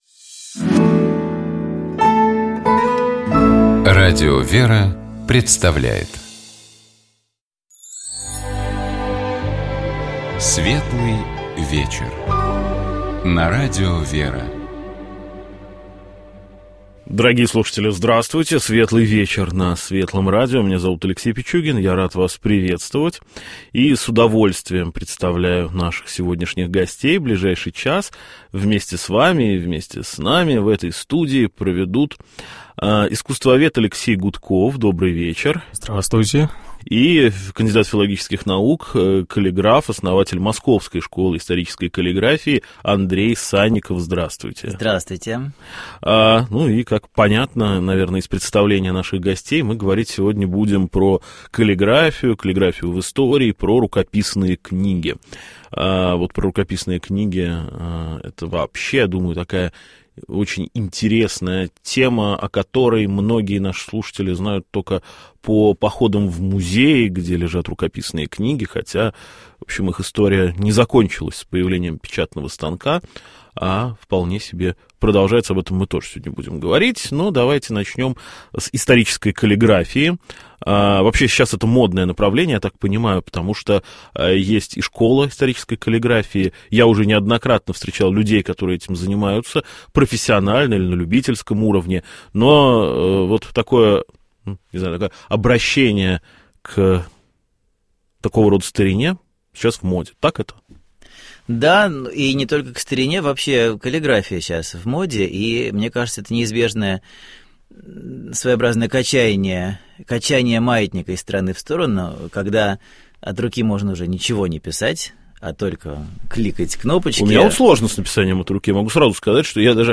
У нас в гостях была певица, композитор